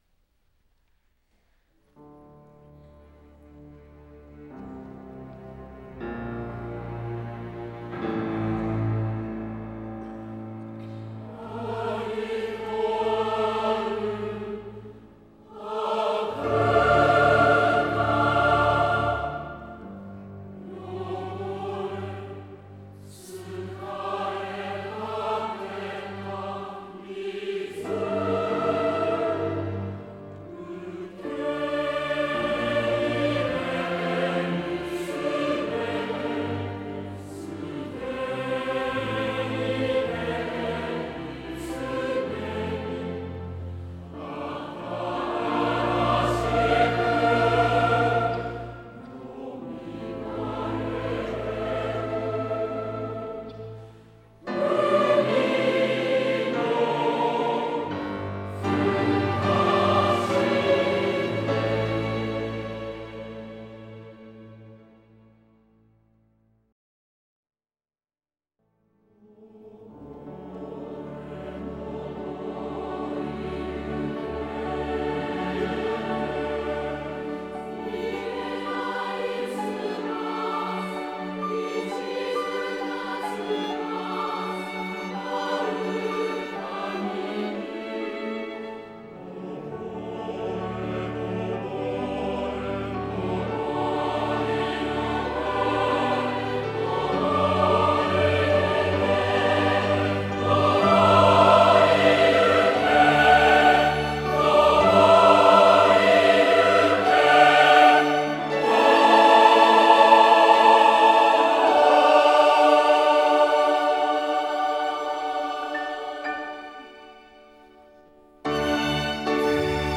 弦楽とピアノ伴奏版